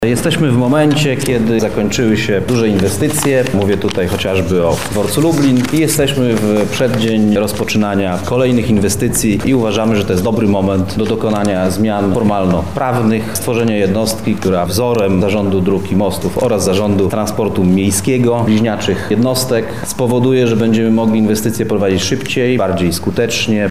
Tomasz Fulara– tłumaczy Tomasz Fulara, Zastępca Prezydenta Lublina.